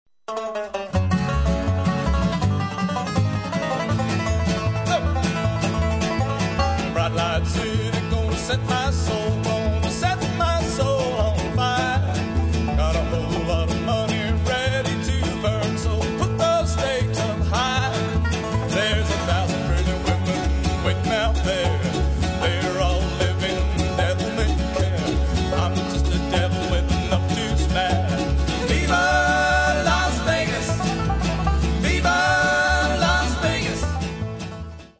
Double Bass, Vocals
Banjo, Vocals